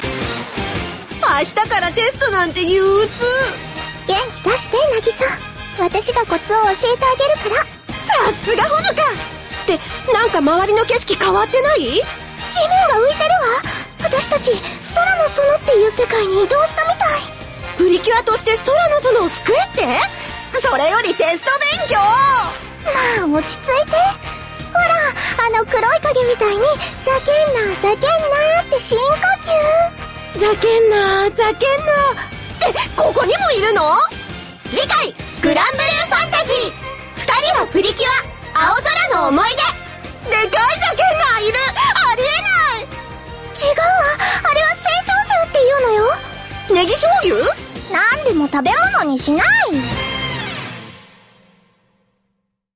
语音及活动按钮